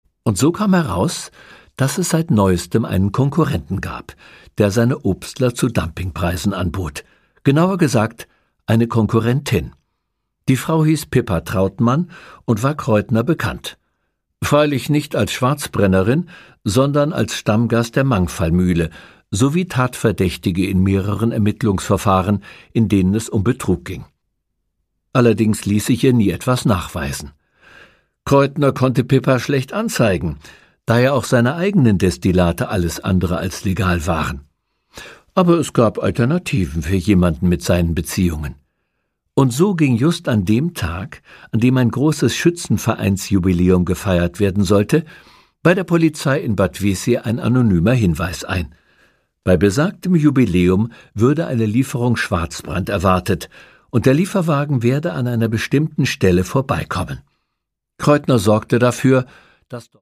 Produkttyp: Hörbuch-Download